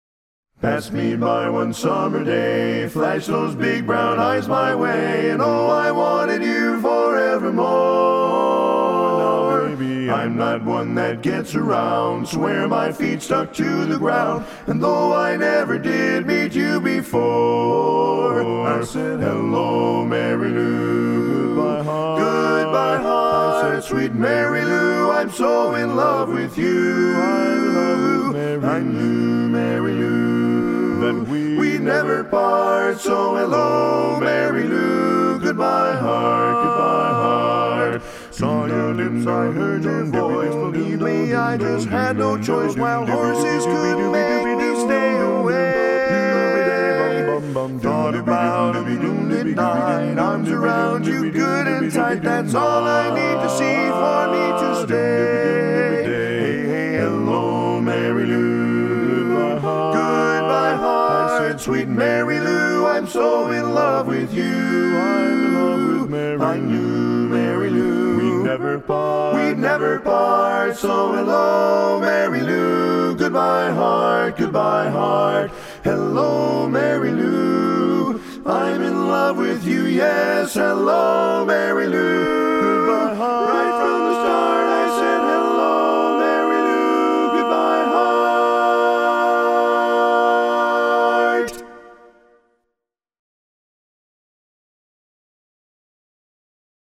Kanawha Kordsmen (chorus)
Up-tempo
B♭ Major
Full Mix